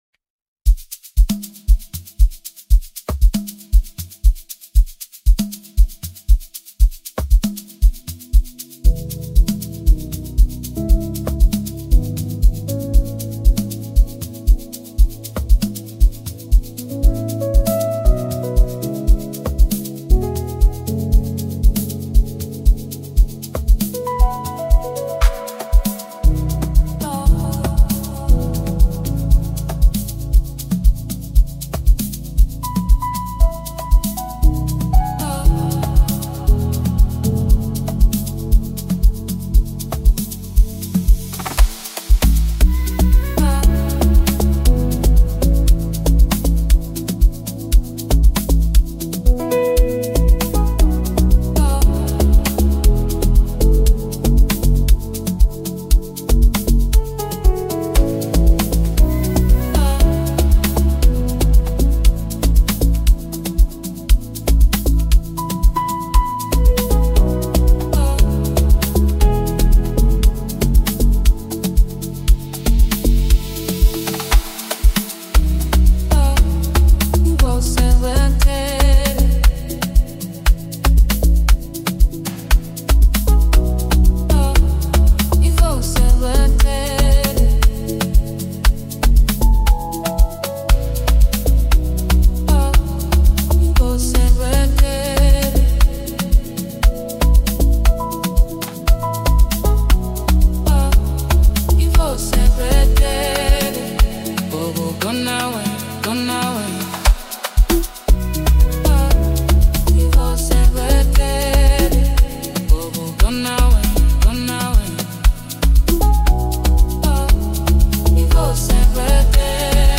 South African singer-songwriter